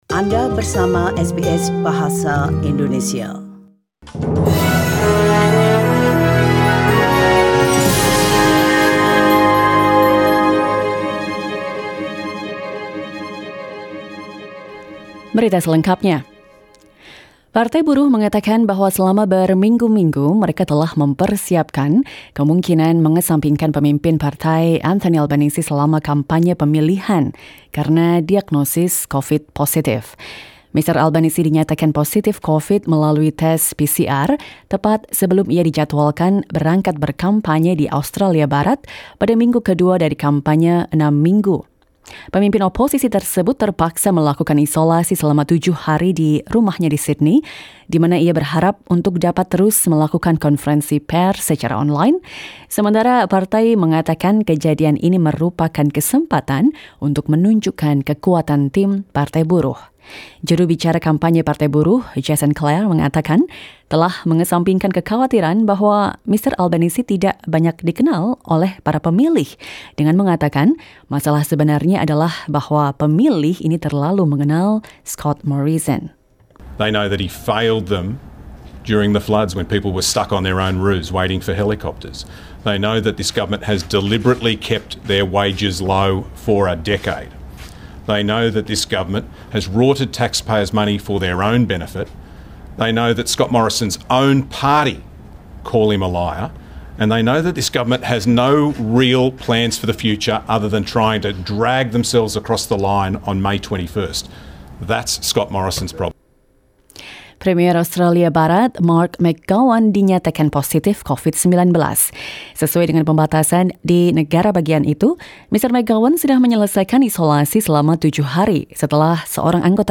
SBS Radio news in Indonesian language, 22 April 2022.